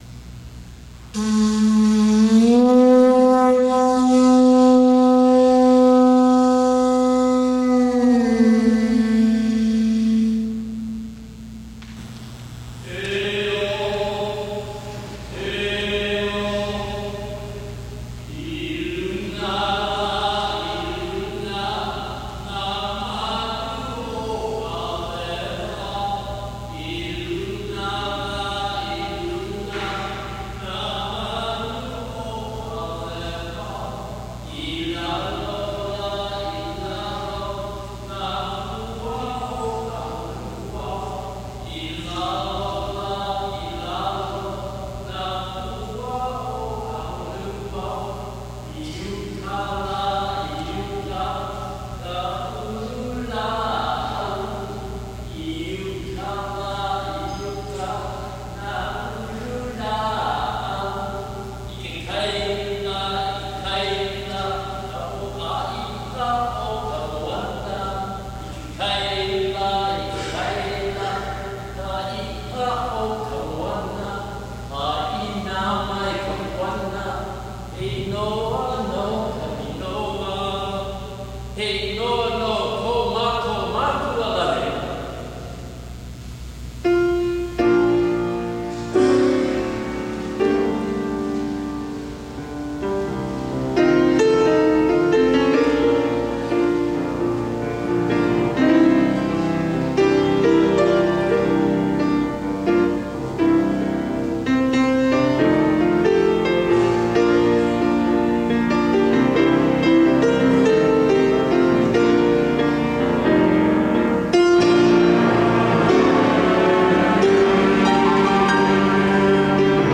Seventeenth ANNUAL LOKOMAIKA'I 'AHA HÏMENI SONGFEST:
Enjoy an evening of Hawaiian-style church music by several Oahu parish choirs.
Malama Pono  (Camacho) St. Ann Choir